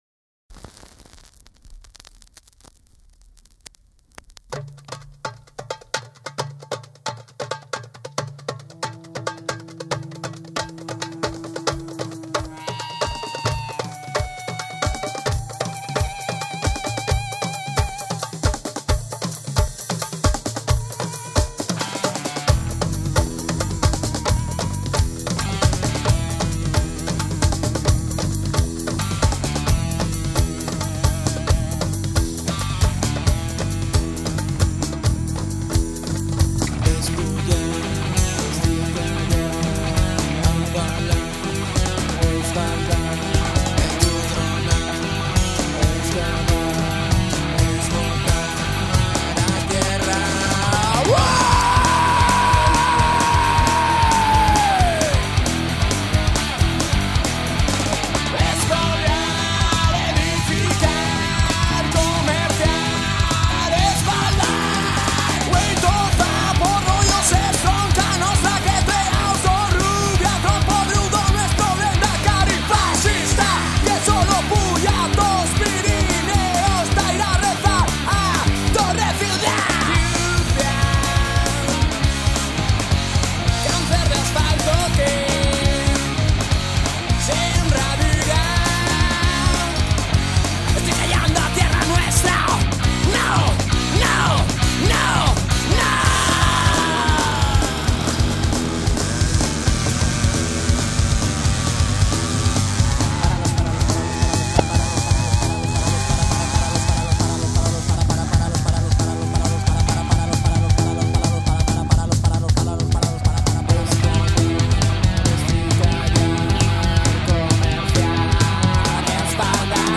(Spagna, political punk-hc)